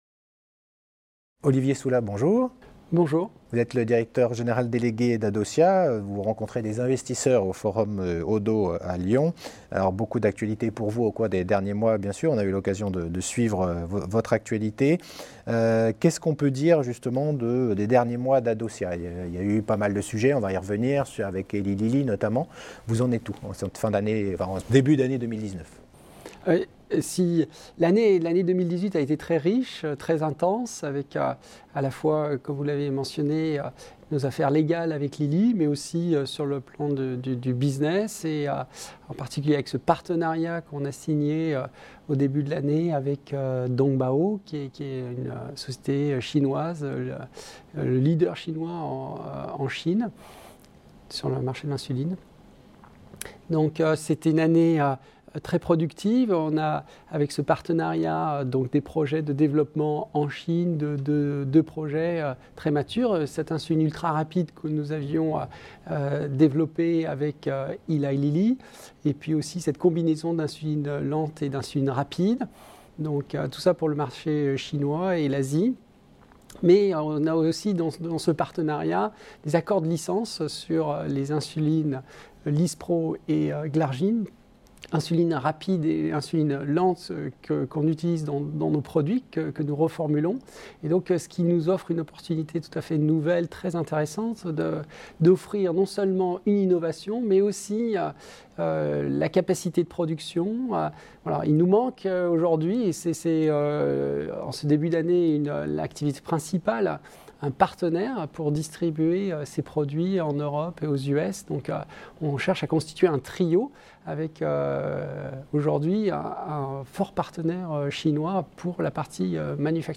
Rencontre au Oddo Forum BHF qui s’est tenu à Lyon le 10 et le 11 janvier 2019